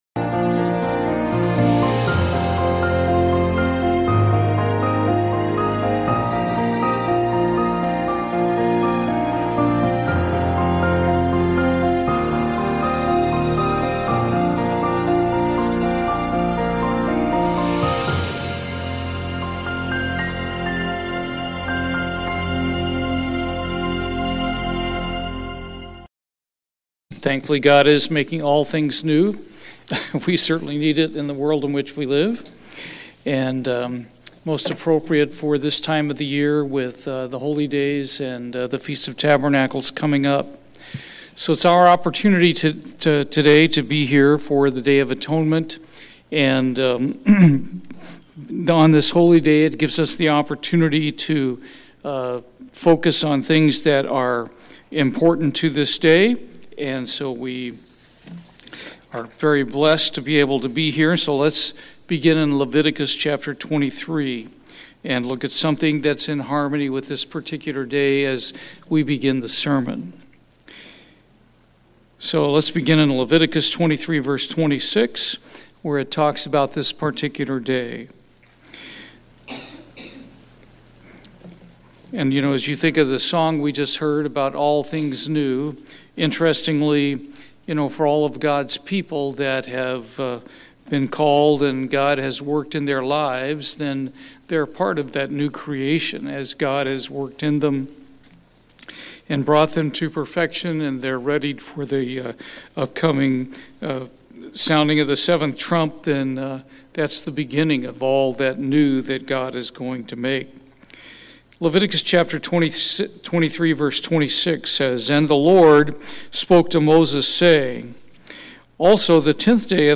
This sermon explains the basic meaning of the Day of Atonement from God's Word.
Given in Houston, TX